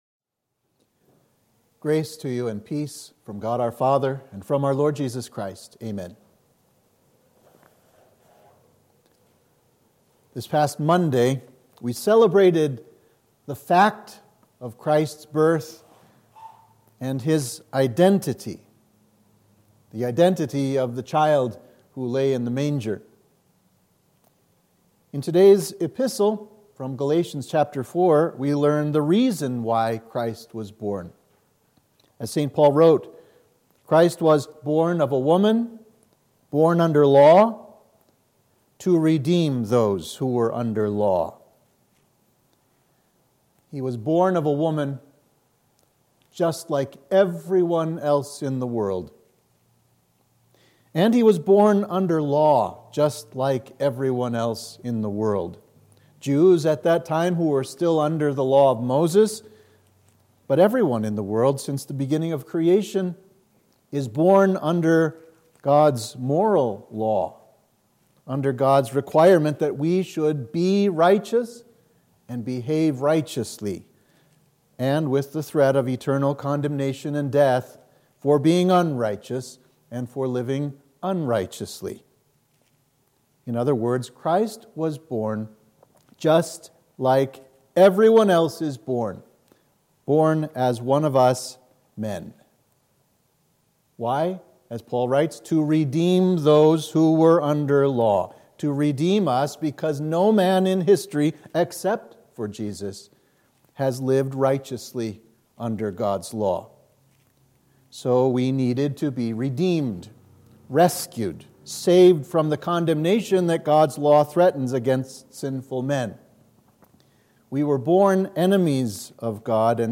Sermon for the First Sunday of Christmas